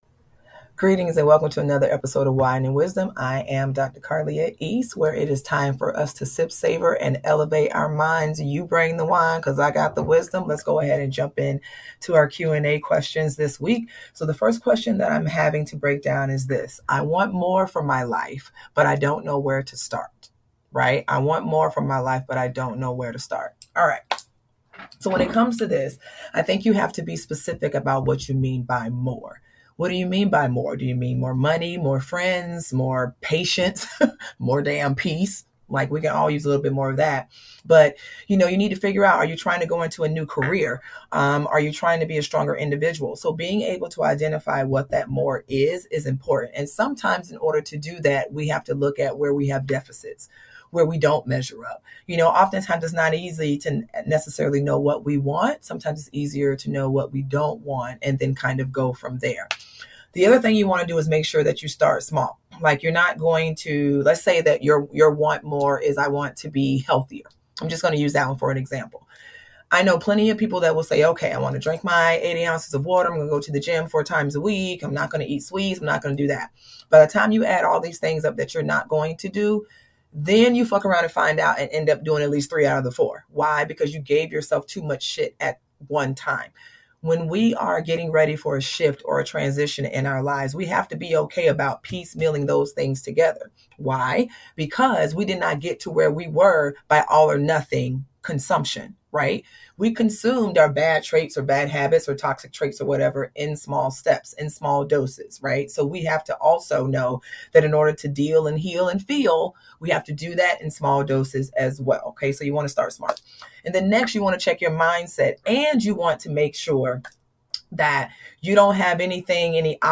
Wine & Wisdom: Q&A